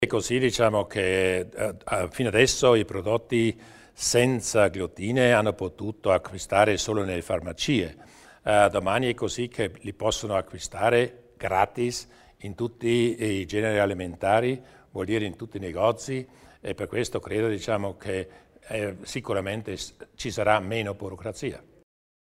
Il Presidente Durnwalder illustra le novità per gli intolleranti agli alimenti contenenti glutine